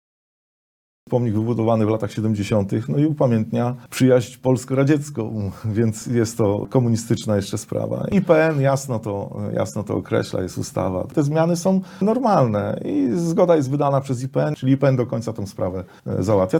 Burmistrz tego miasteczka, Szymon Klimko, zapowiada, że za kilka tygodni pozostaną po nim tylko zdjęcia.